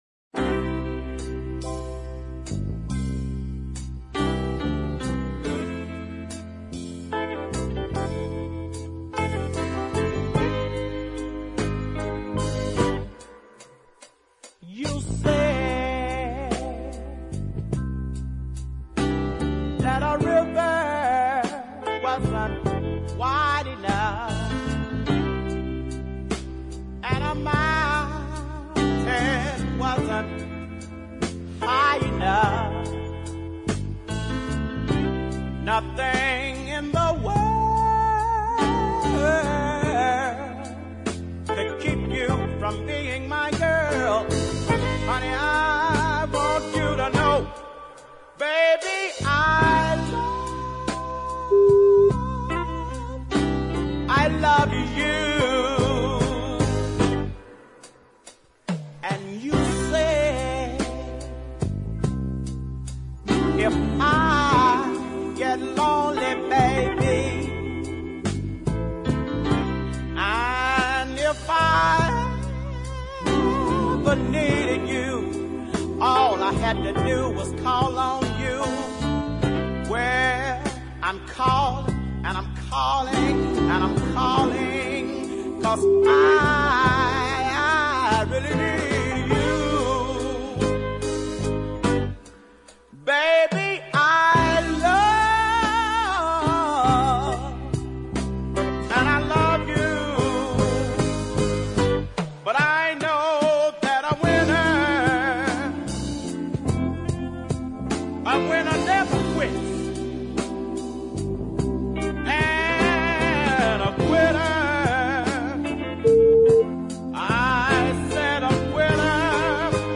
southern soul
This featured the superb deep soul of Listen